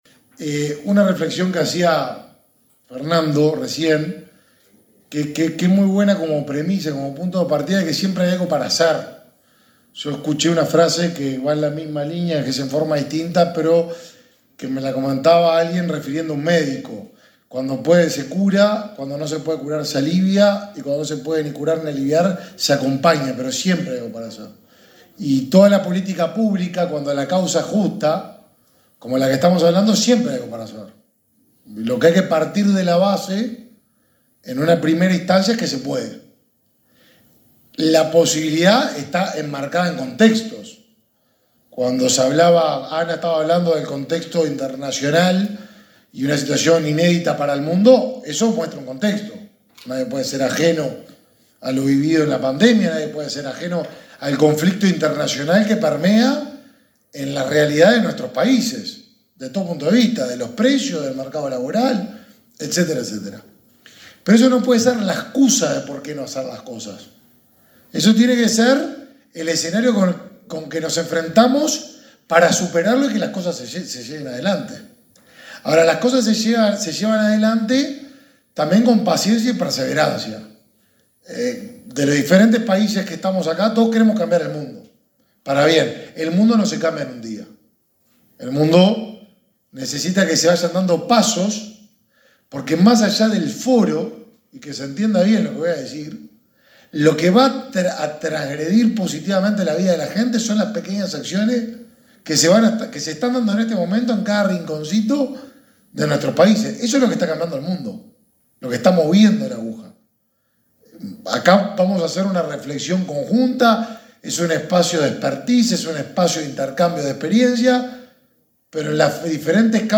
Palabras del ministro de Desarrollo Social, Martín Lema
El ministro de Desarrollo Social, Martín Lema, participó en la apertura del VIII Foro Iberoamérica Incluye, realizado en el hotel Radisson, en